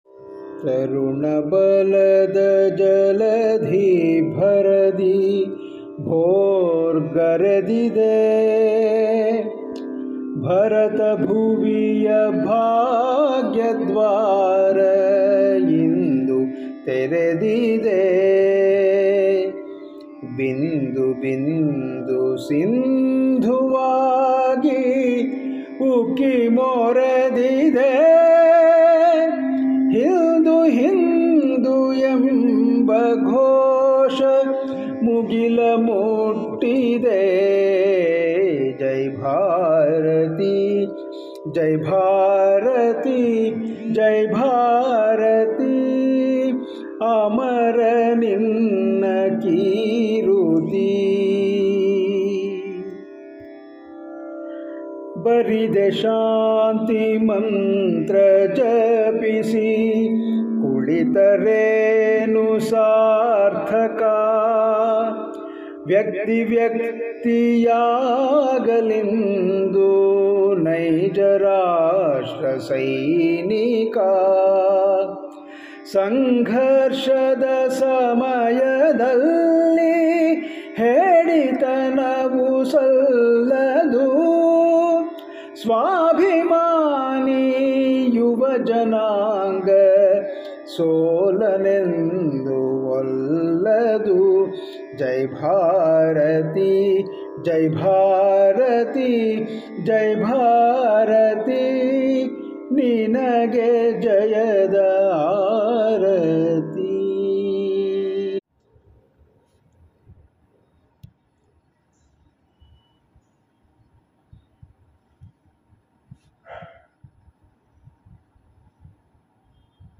Patriotic Songs Collections
Solo